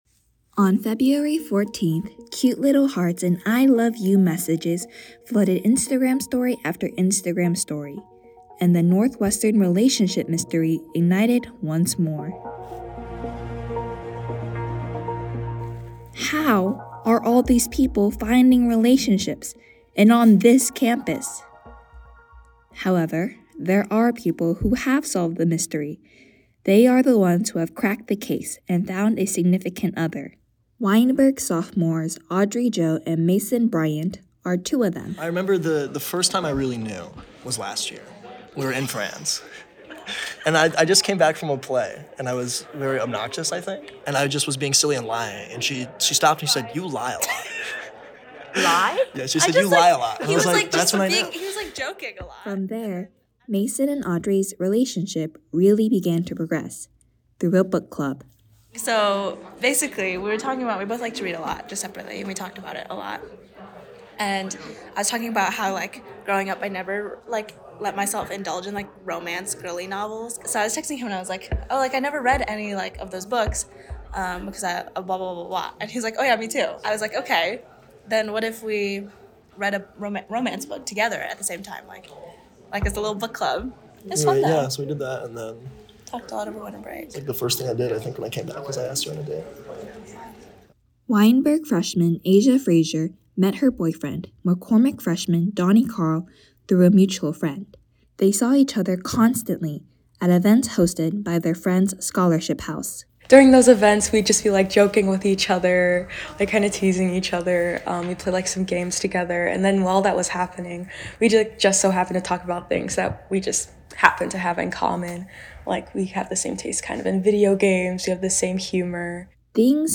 This story originally aired as part of our WNUR News: Unsolved special broadcast.